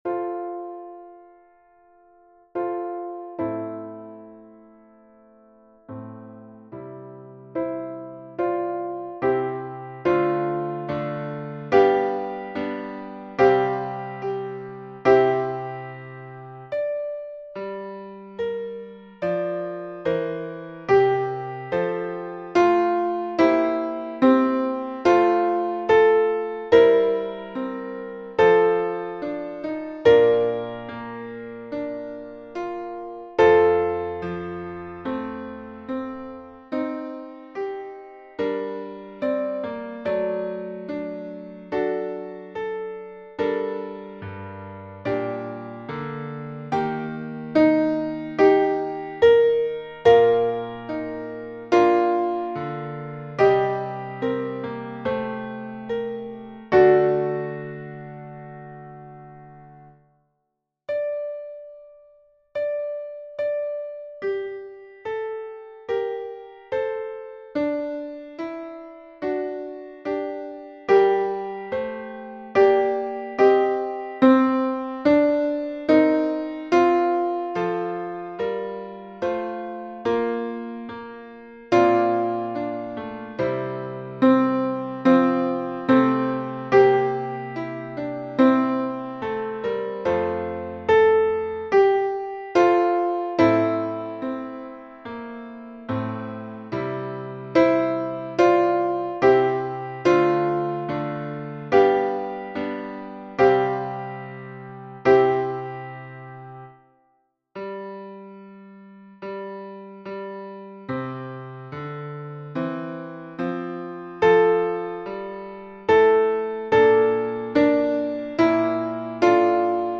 ALTO (piano)